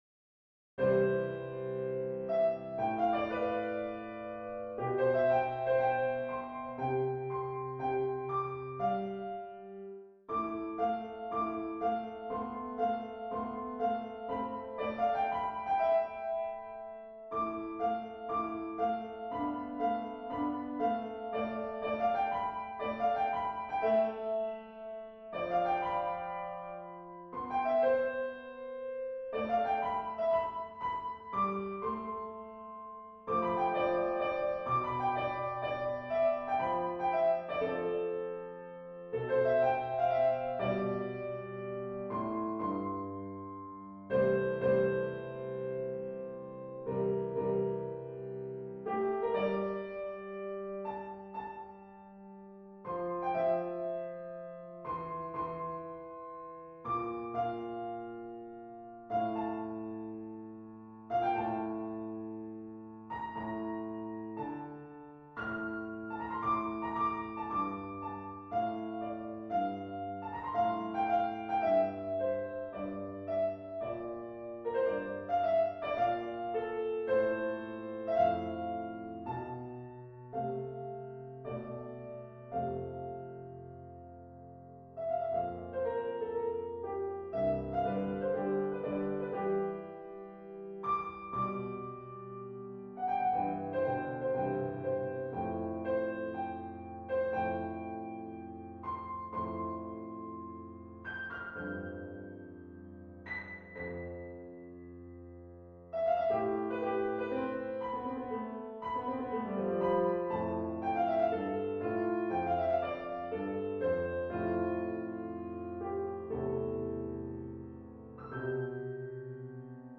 A short piece for piano, slightly impressionistic